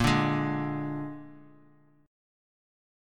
Bb7 Chord
Listen to Bb7 strummed